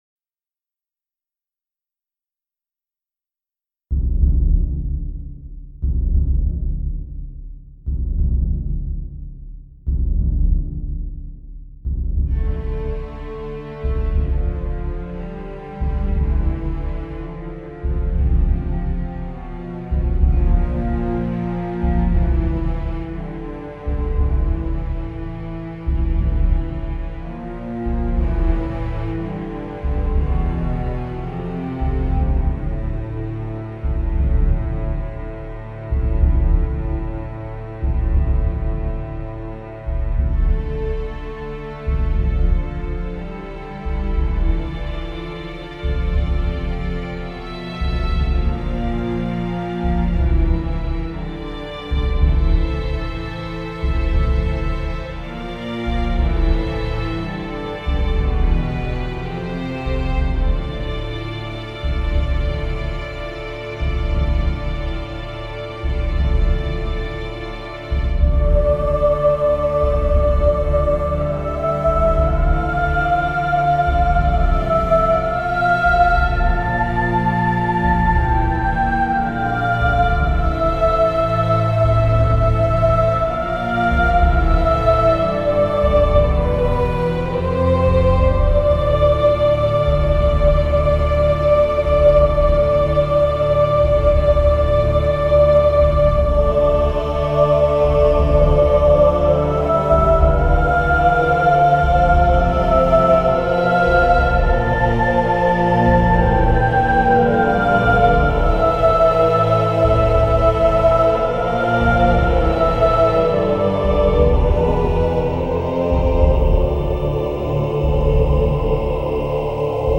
专辑风格：Gothic/Atmospheric Metal